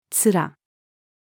面-つら-female.mp3